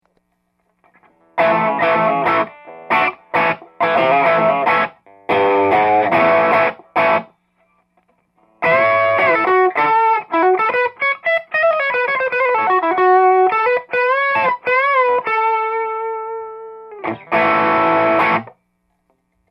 使用機材ですが、ギターはもちろんLEAD2(ハムキャンセルコイルは取り外し、ピックガードも純正品を装着、ピックアップはリアを使用) 、アンプはTwo-Rock TOPAZをクリーンセッティングで、録音はEDIROLのR-1を使用しました。